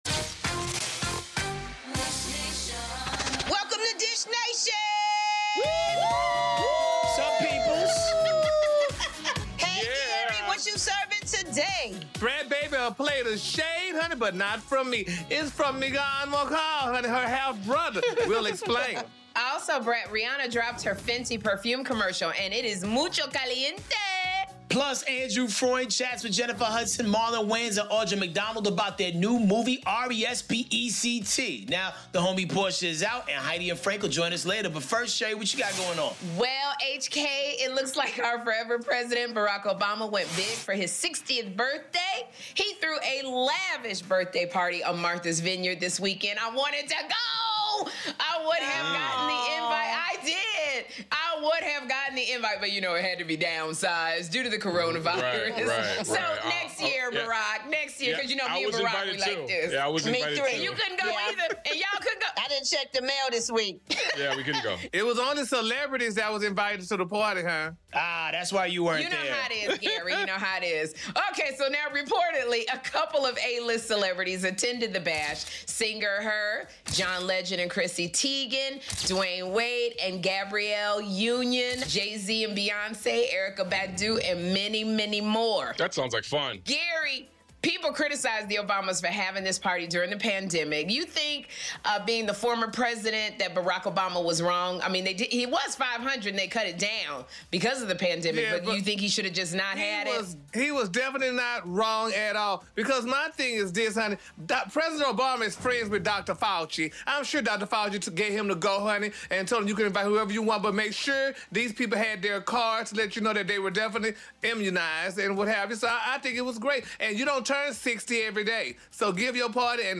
Jennifer Hudson, Marlon Wayans and the 'Respect' cast dish with us! Plus, Joshua Jackson moves his wife Jodie Turner-Smith into his childhood home, and find out which NFL coach is crazy about his coffee!